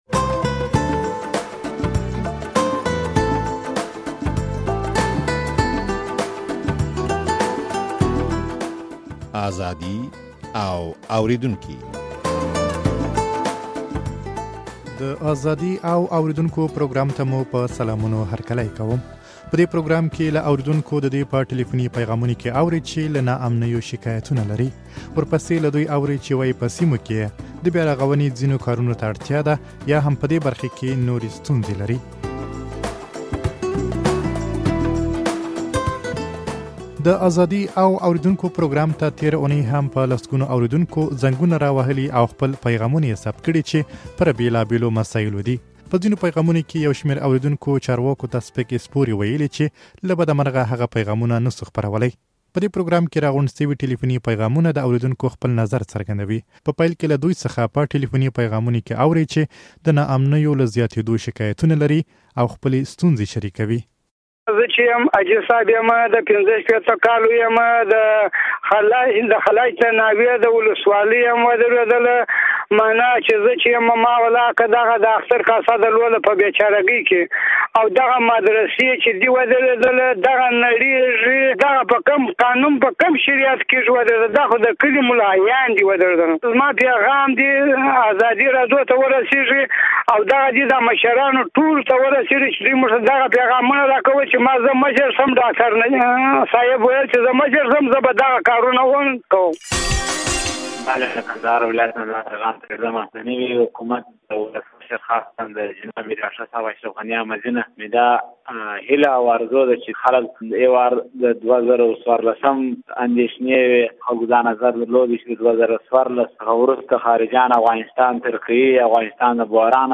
په دې پروګرام کې له اورېدونکو د دوى په ټليفوني پيغامونو کې اورئ چې له ناامنيو شکايتونه لري.